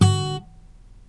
木吉他 " yamahfingerpluck
描述：拔掉雅马哈木吉他上的音符，用我忠实的廉价夹式电容话筒（很快就会被替换）录制。
Tag: 声学 手指甲挑 吉他 拔毛